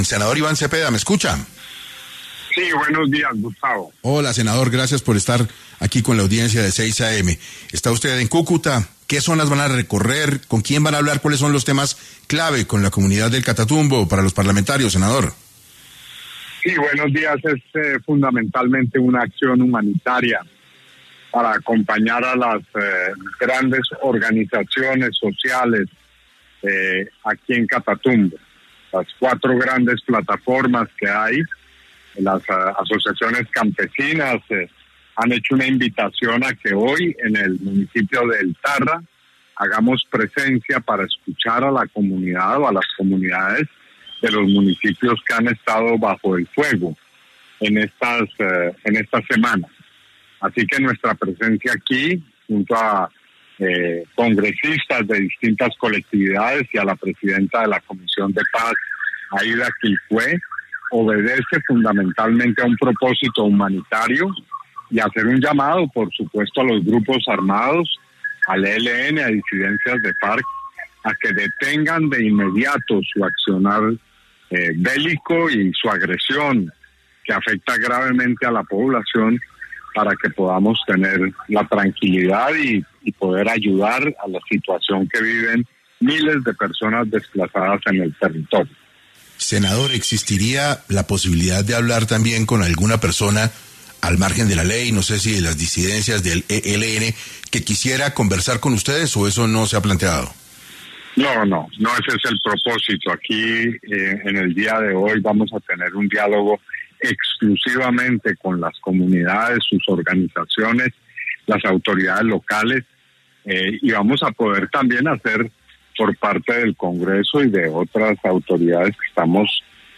En Caracol Radio estuvo el senador Iván Cepeda
Mientras el senador Iván Cepeda se dirigía al Catatumbo, conversó con Caracol Radio sobre la labor que desarrollará el Gobierno en esta zona del país.